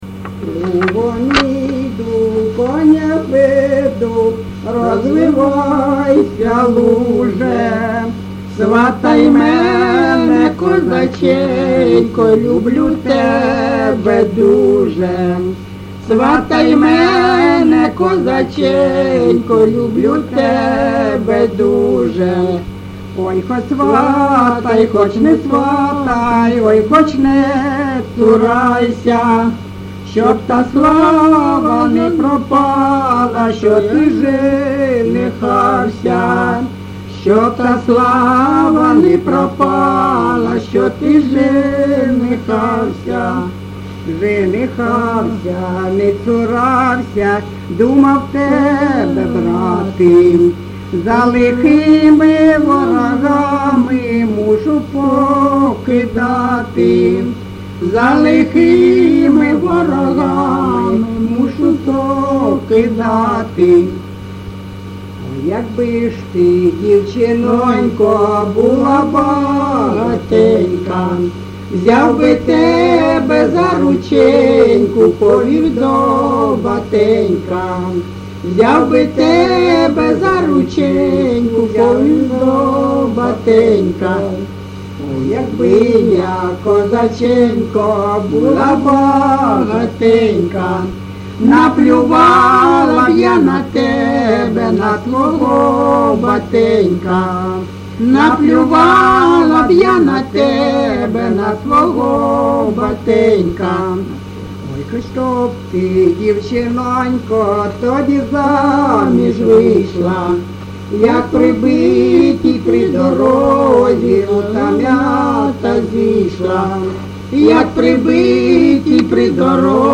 ЖанрПісні з особистого та родинного життя, Козацькі
Місце записус. Калинове Костянтинівський (Краматорський) район, Донецька обл., Україна, Слобожанщина